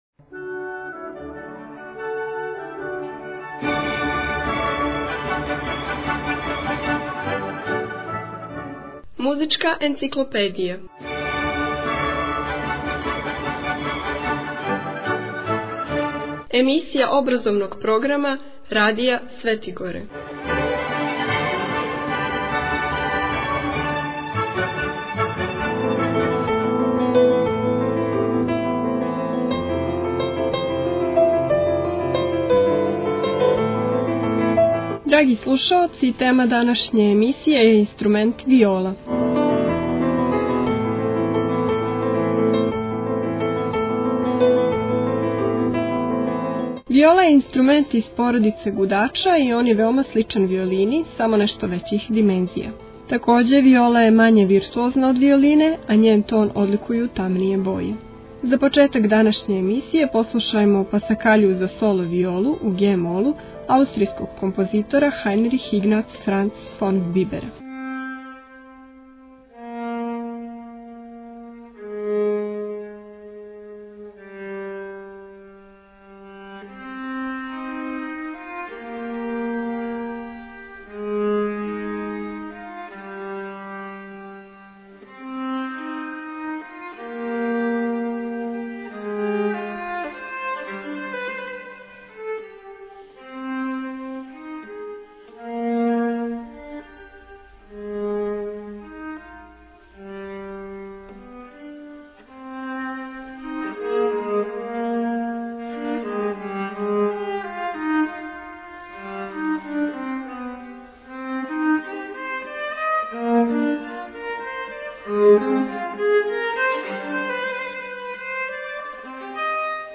Албум: Muzicka enciklopedija Година: 2016 Величина: 32:45 минута (4.69 МБ) Формат: MP3 Mono 11kHz 20Kbps (VBR) У емисији "Музичка енциклопедија" можете чути инструмент виолу и сазнати нешто више о њој.